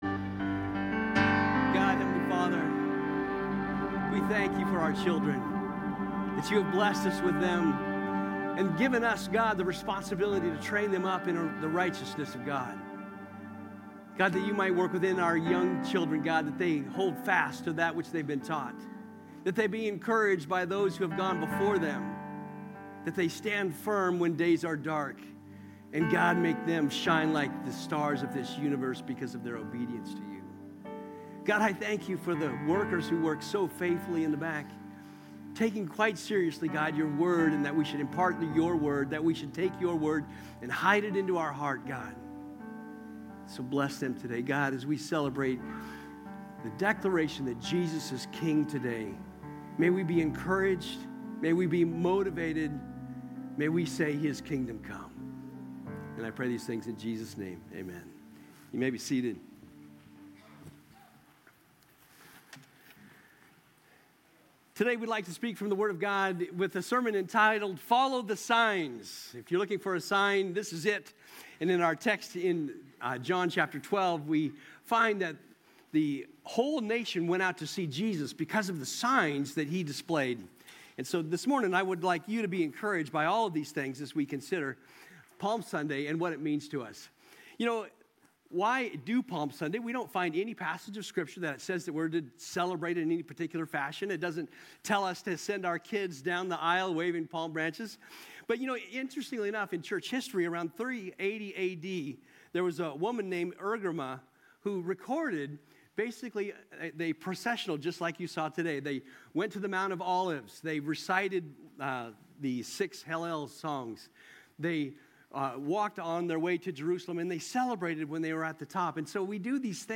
From Series: "Topical Sermons"